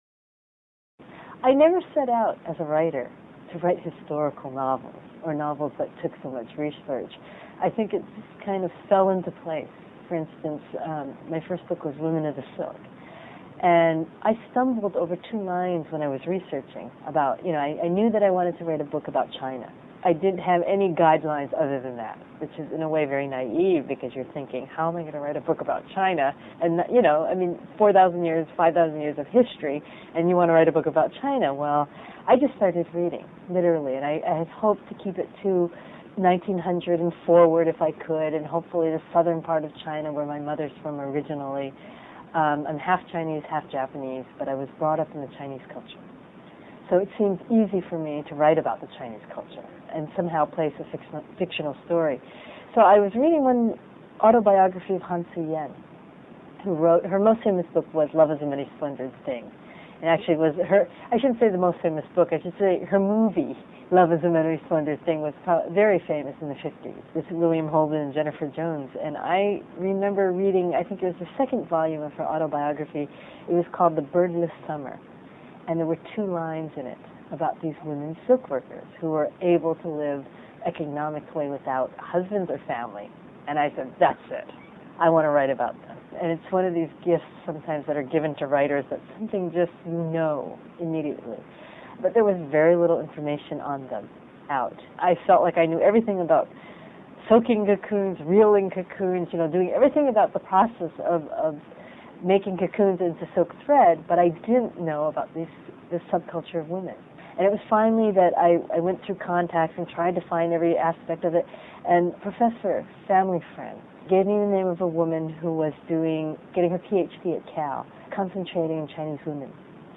Q&A with Gail Tsukiyama